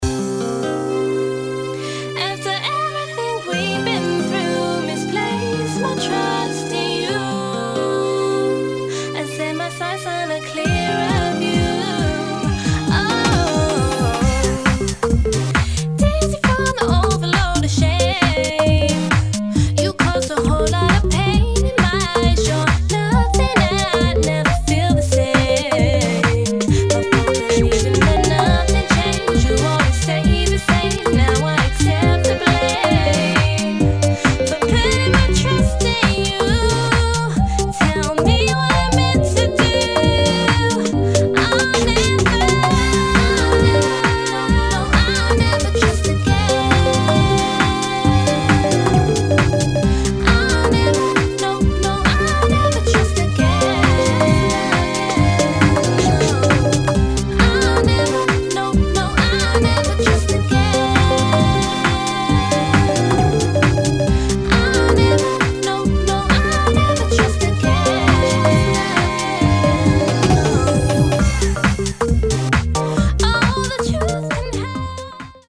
[ UK GARAGE ]